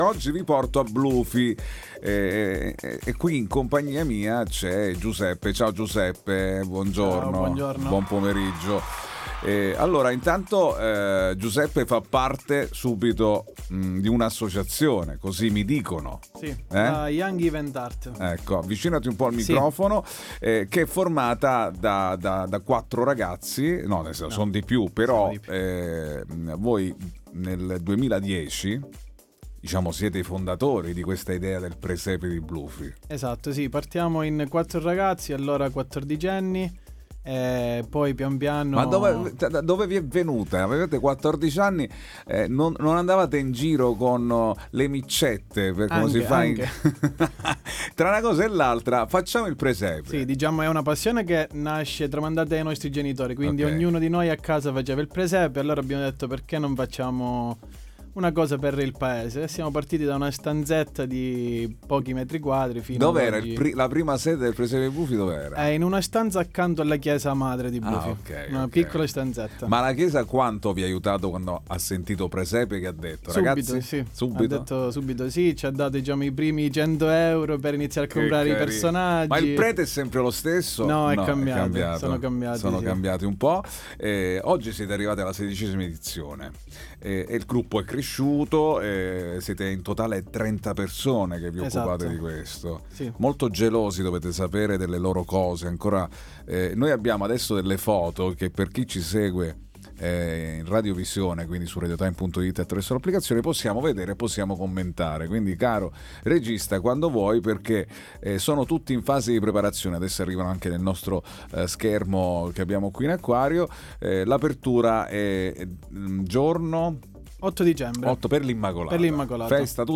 All Inclusive Interviste 24/11/2025 12:00:00 AM